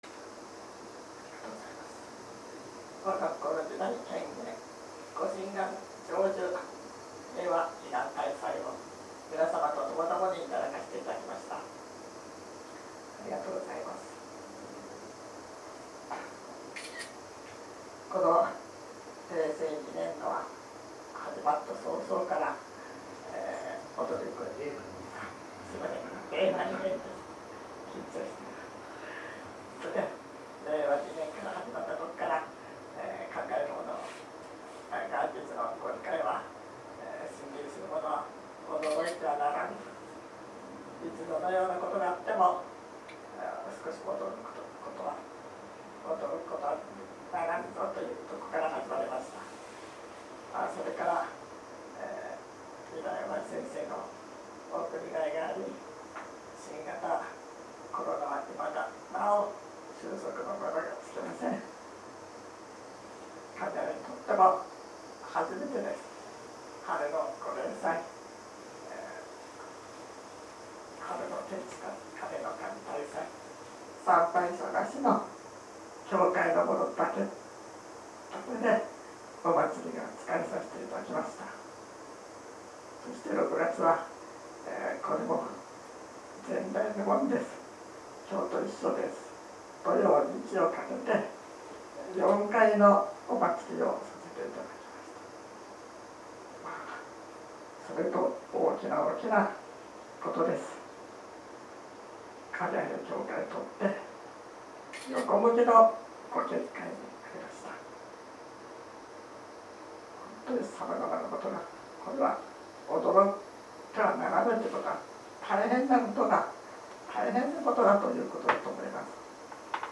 平和祈願大祭教話